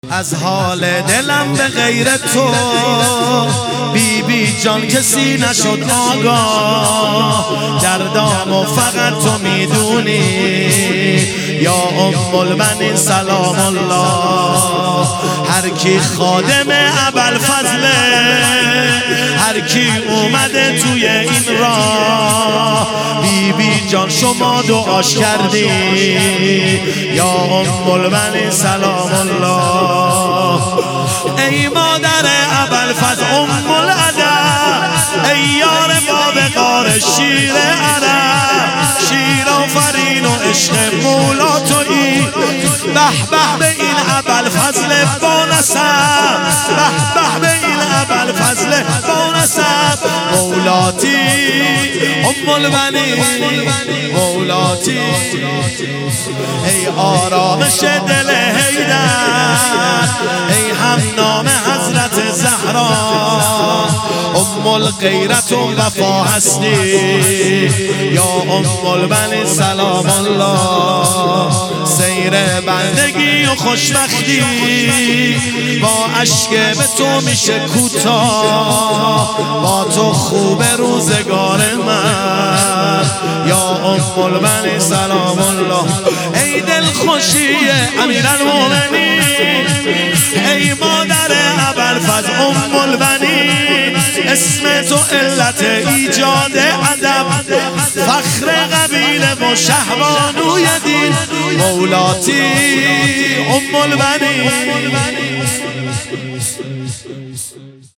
ایام فاطمیه 1399 | هیئت شاهزاده علی اصغر کاشان